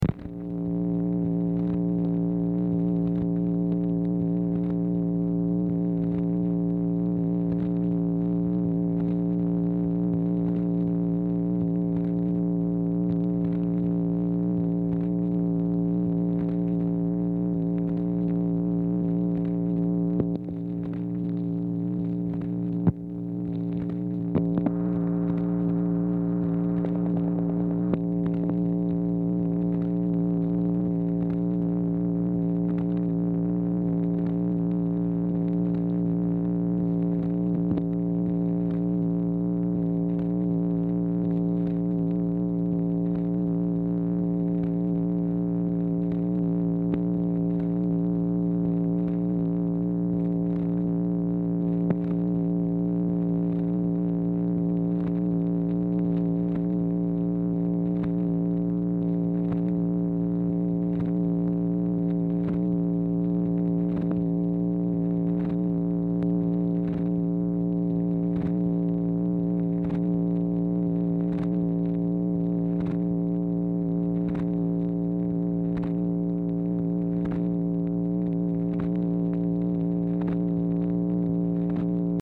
Telephone conversation # 3715, sound recording, MACHINE NOISE, 6/12/1964, time unknown | Discover LBJ
Format Dictation belt
Specific Item Type Telephone conversation